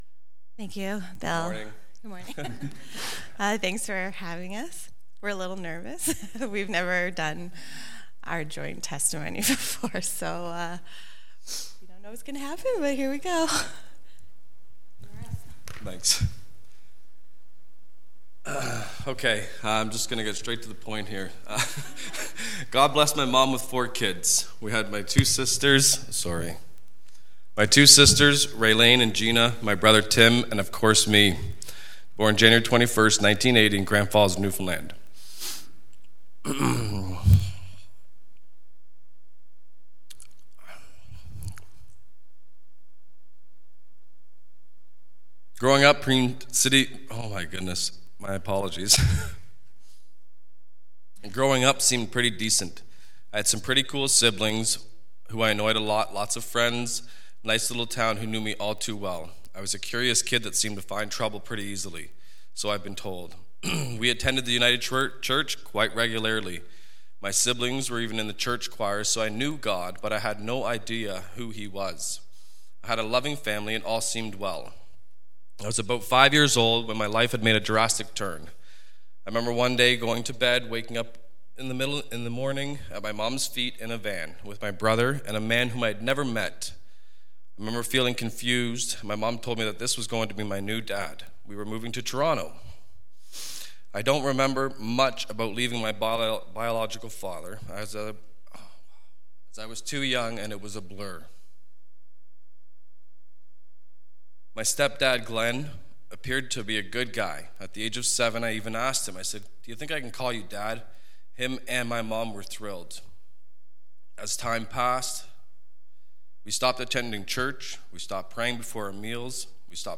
Good Friday Testimonial